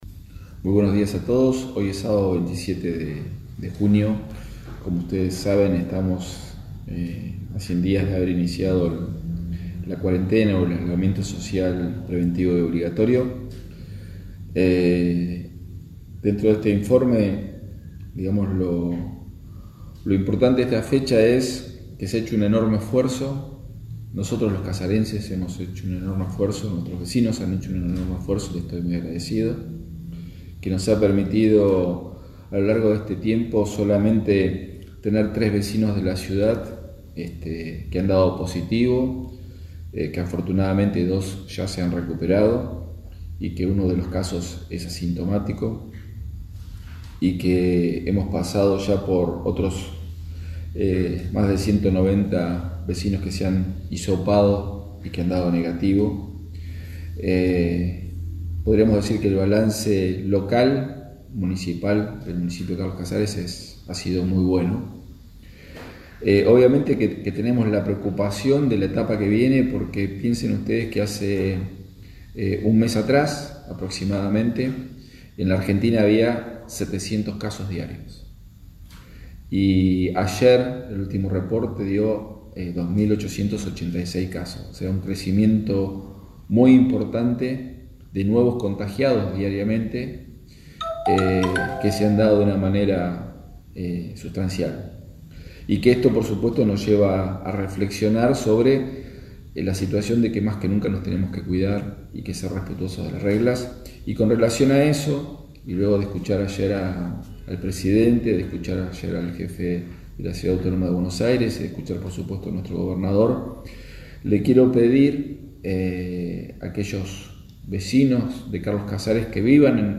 ‘Si aparece un nuevo caso en Carlos Casares retrocederemos ‘, dijo Walter Torchio al hacer el informe de cada día sobre el Coronavirus en Carlos Casares.